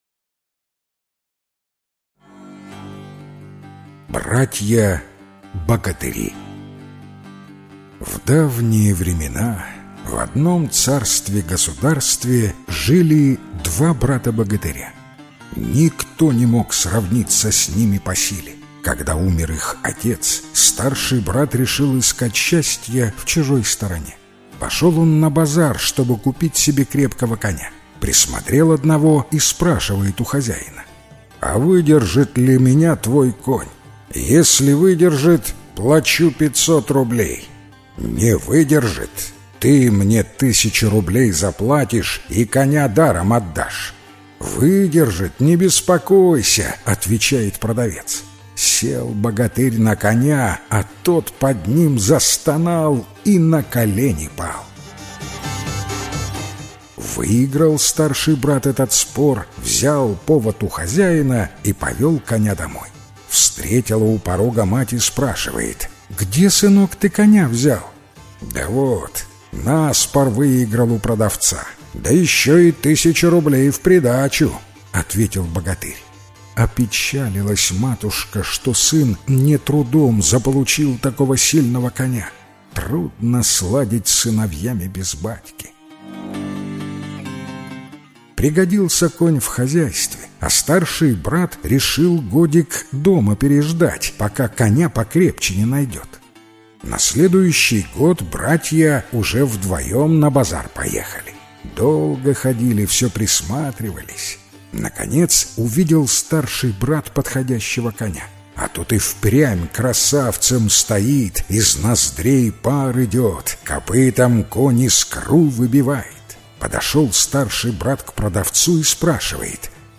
Братья-богатыри - белорусская аудиосказка - слушать онлайн